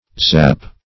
zap \zap\ (z[a^]p), v. t.